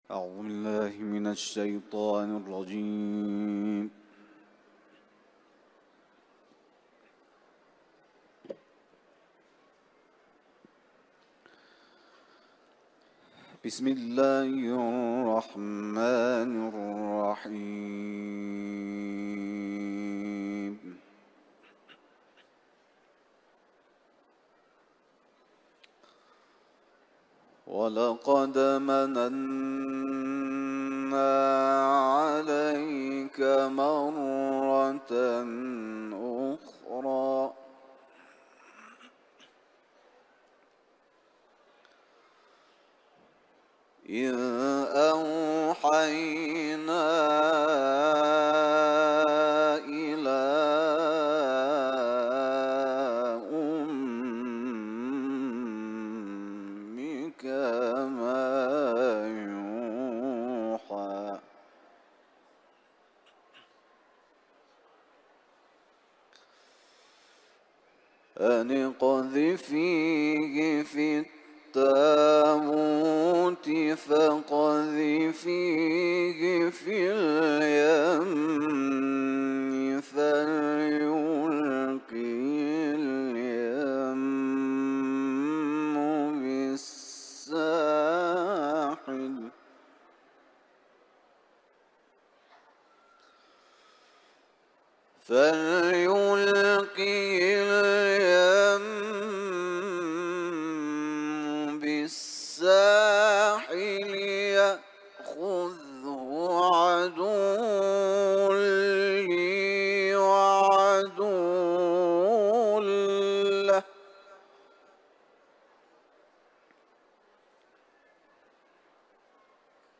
Tâhâ suresinden ayetler tilavet etti.